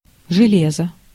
Ääntäminen
IPA: [ʐɨ.ˈlʲe.zə]